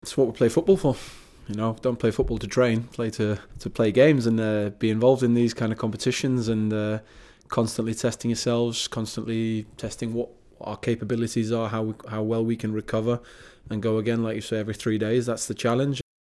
Schmeichel says there's no reason to ease up.